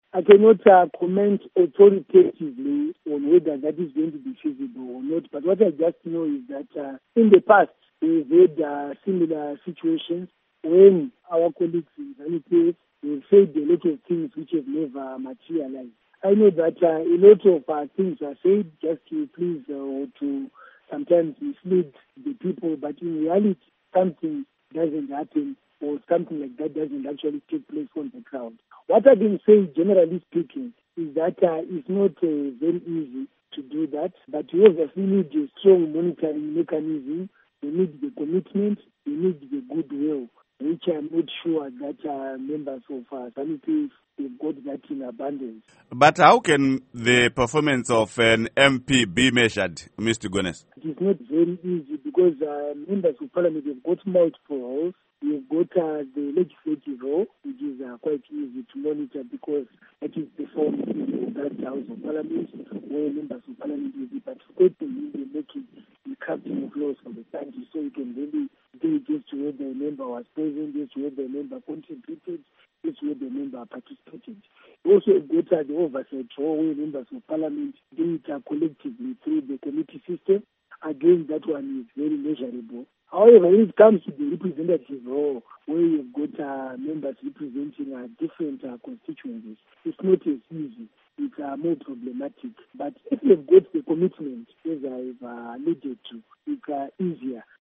Interview With Innocent Gonese